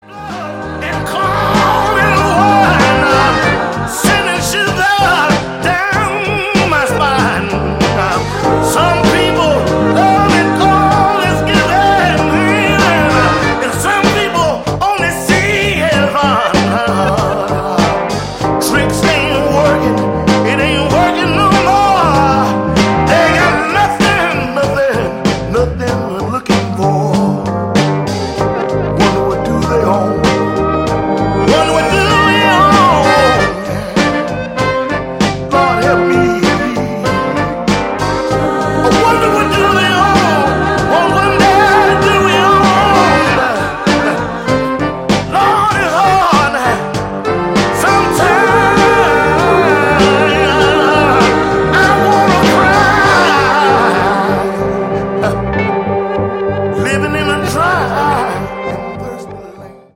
old school soul albums
has a strong, throaty voice